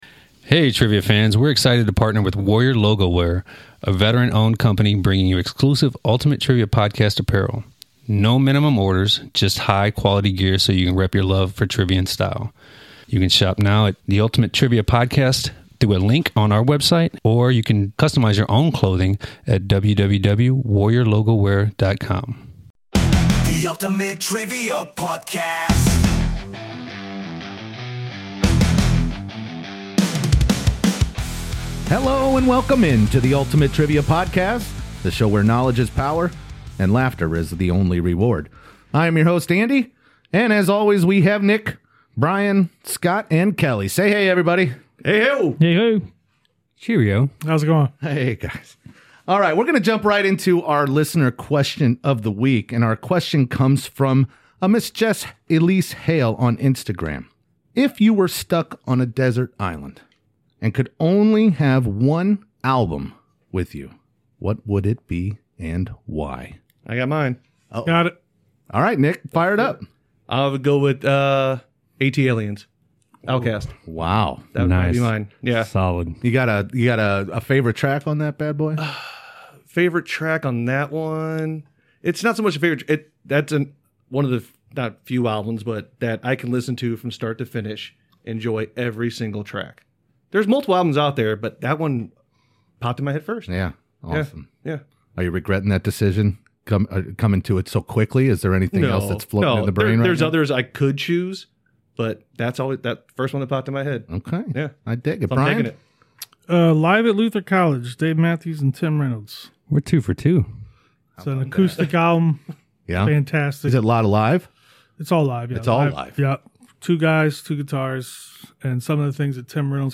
The vibe? Competitive, fast-paced, and always entertaining. Each week, they tackle 10 well-balanced questions across history, science, pop culture, and more—mixing in fascinating facts, fierce competition, and the kind of banter only true friends (and rivals) can deliver.